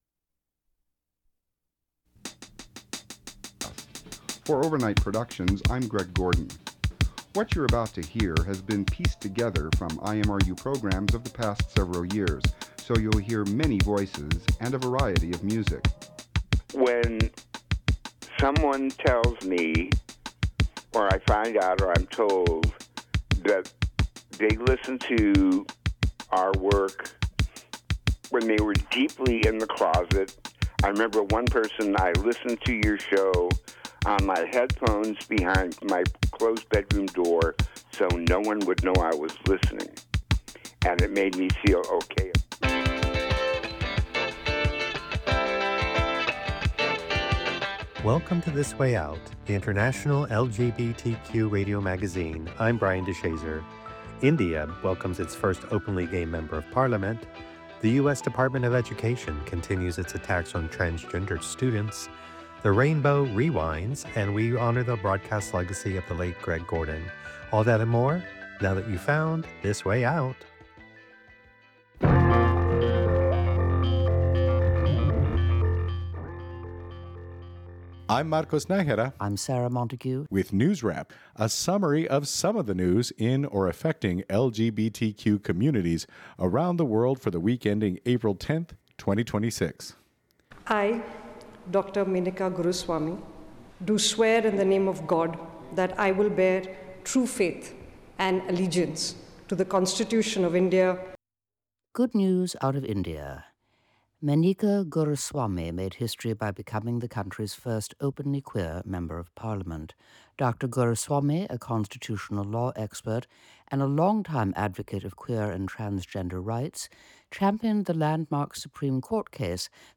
The International LGBTQ Radio Magazine